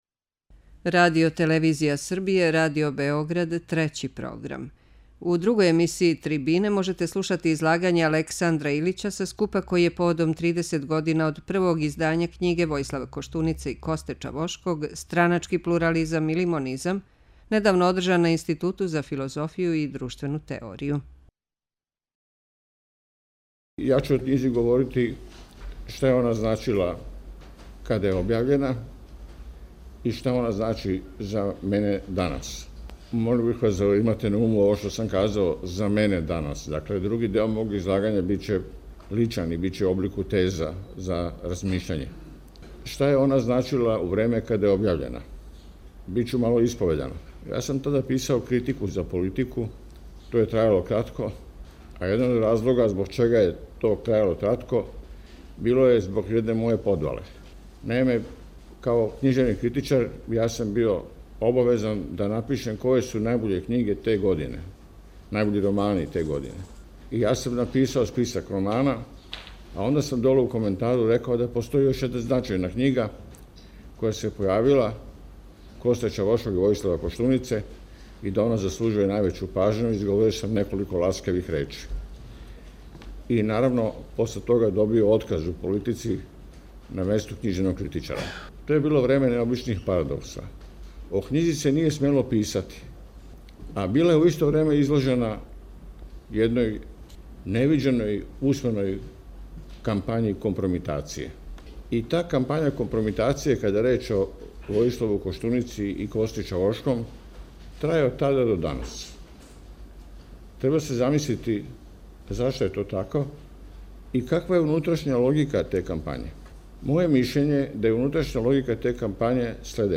У две емисије ТРИБИНЕ можете слушати излагања са скупа који је поводом тридесетогодишњице првог издања књиге Војислава Коштунице и Косте Чавошког 'Страначки плурализам или монизам' недавно одржан на Институту за филозофију и друштвену теорију.